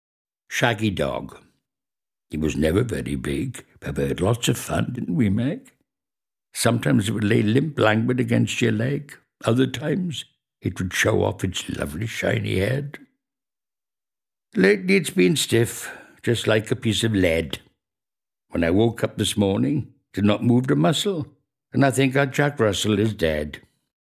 Click here to play poem read by Victor Spinetti